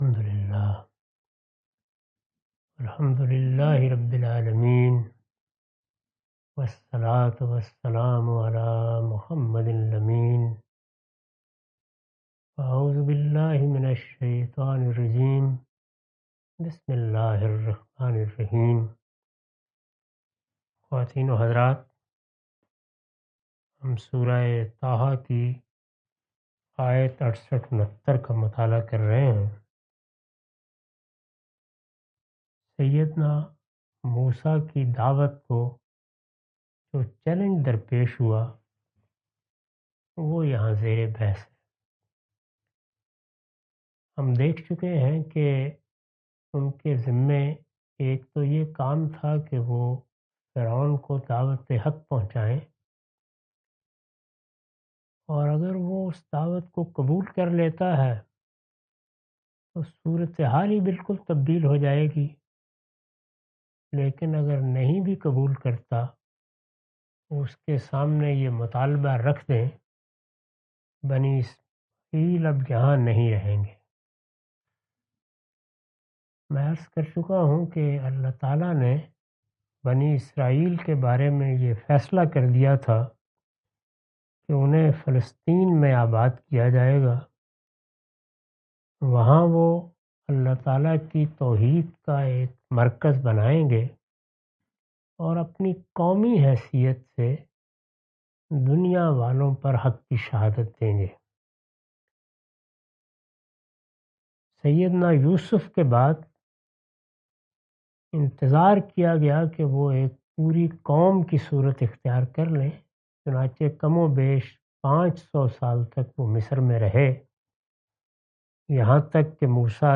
Surah Taha A lecture of Tafseer-ul-Quran – Al-Bayan by Javed Ahmad Ghamidi. Commentary and explanation of verses 70-71.